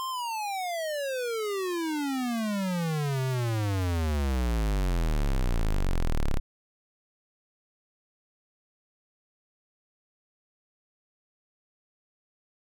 canon
Perfect for bang, cannon, explosion.
bang cannon explosion shoot sound effect free sound royalty free Gaming